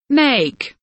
make kelimesinin anlamı, resimli anlatımı ve sesli okunuşu